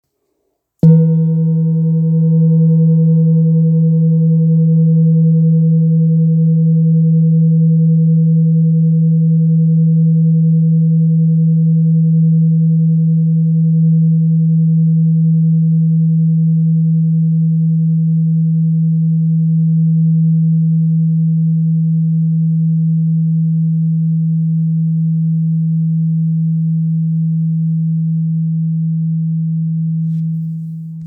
Kopre Singing Bowl, Buddhist Hand Beaten, Antique Finishing
Material Seven Bronze Metal
It is accessible both in high tone and low tone .
In any case, it is likewise famous for enduring sounds.